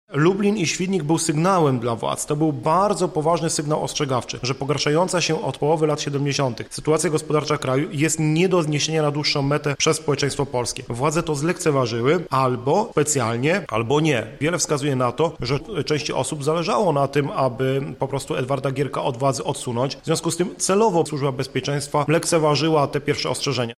historyk.